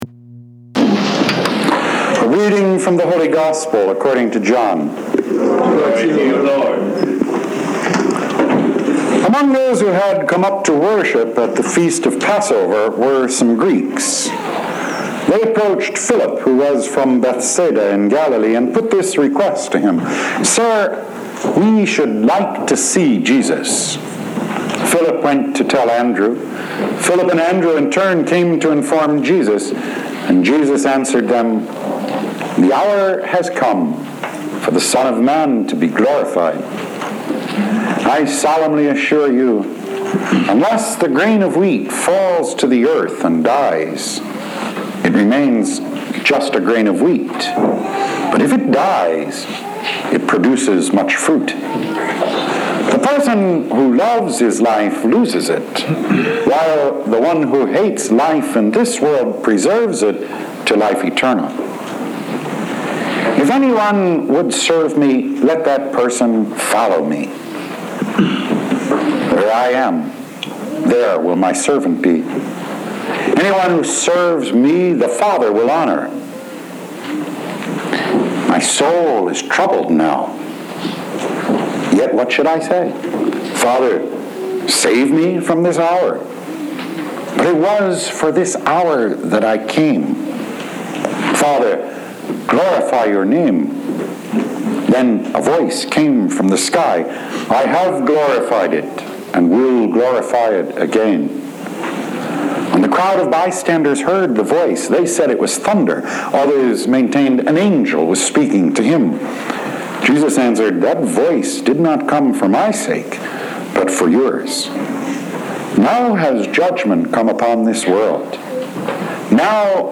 Covenant – Weekly Homilies